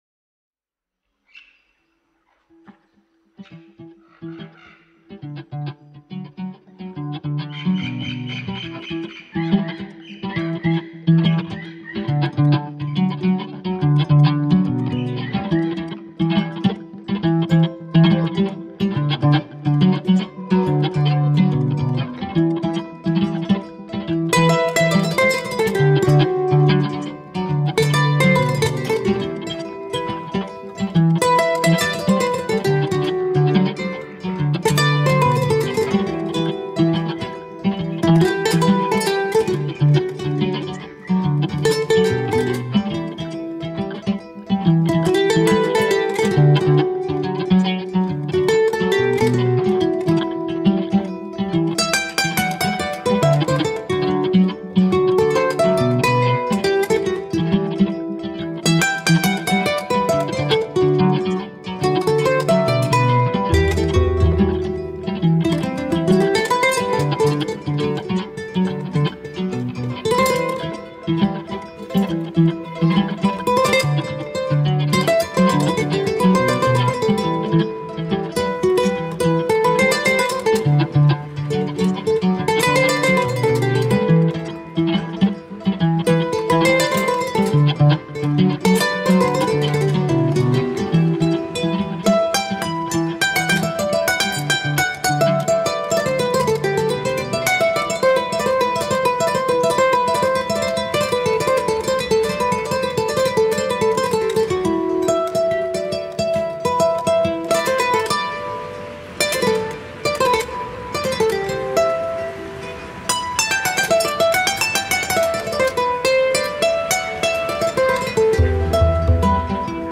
Début de cérémonie bercé par l’harmonie magique d’une kora